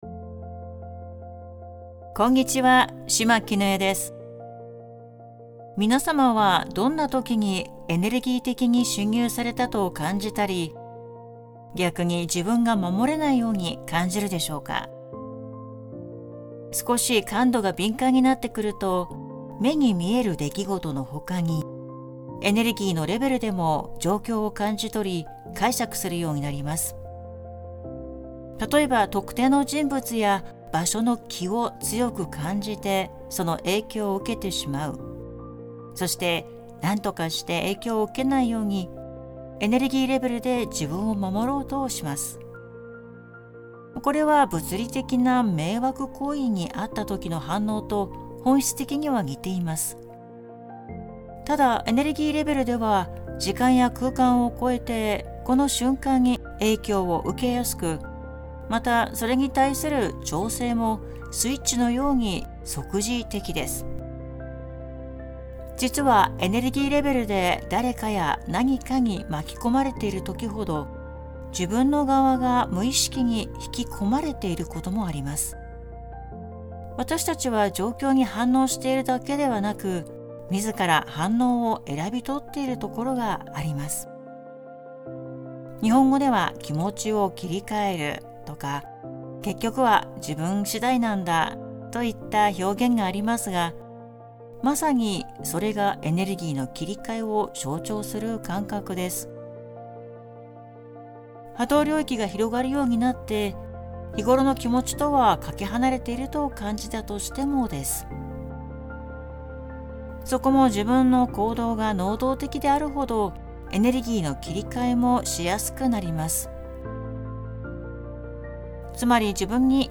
メッセージは、文字と音声でお届けしています。